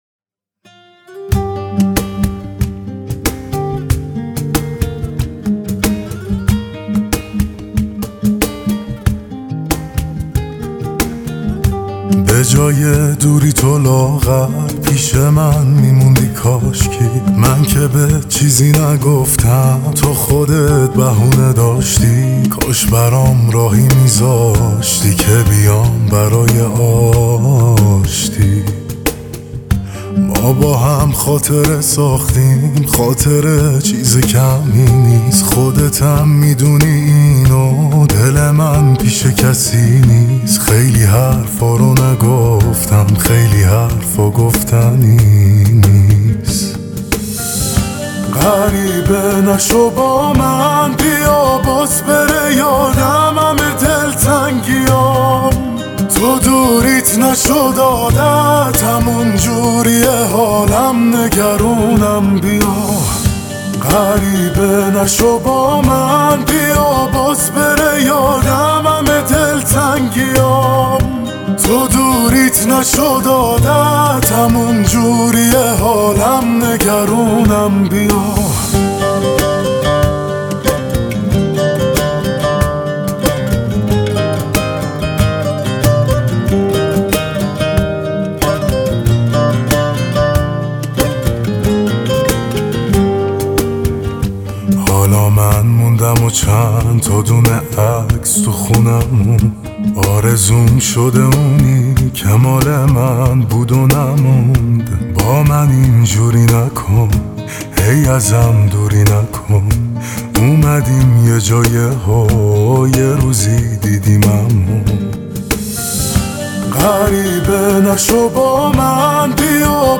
این آهنگ یه داستان عاشقانه‌ی پر از دلشوره و احساسه